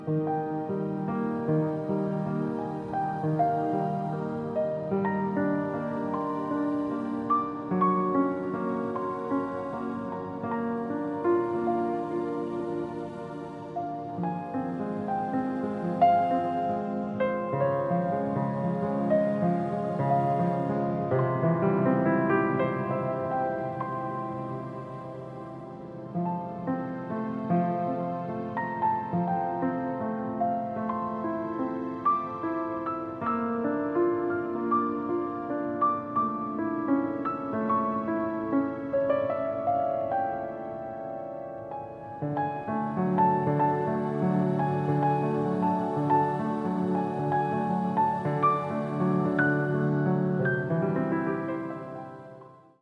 Music to Enhance Your own personal Home Spa Experience